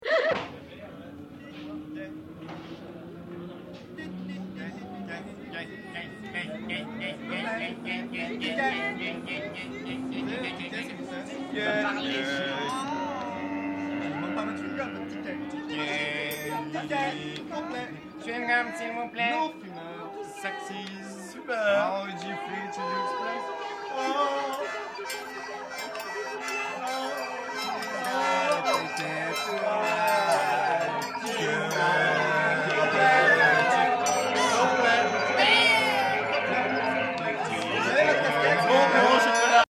sax ténor, Fender Rhodes, voix, percussions
flûtes, sax soprano & alto, appeaux, voix,